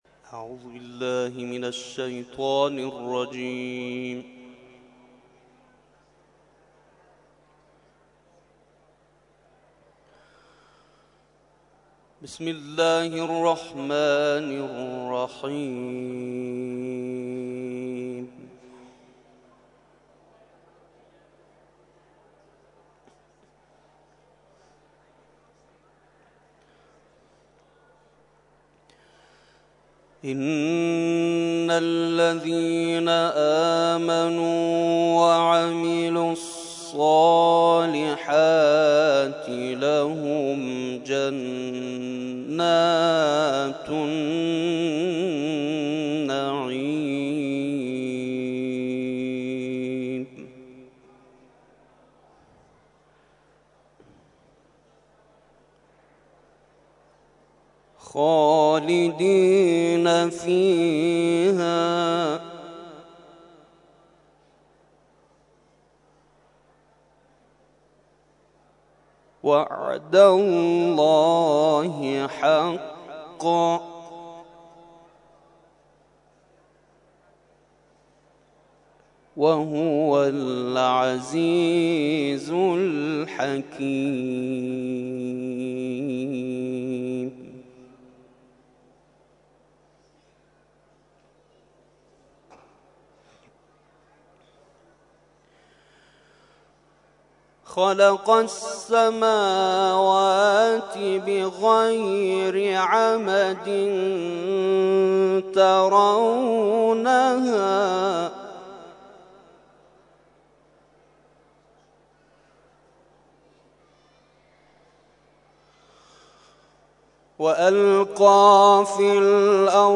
تلاوت ظهر